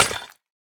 Minecraft Version Minecraft Version latest Latest Release | Latest Snapshot latest / assets / minecraft / sounds / block / decorated_pot / shatter2.ogg Compare With Compare With Latest Release | Latest Snapshot
shatter2.ogg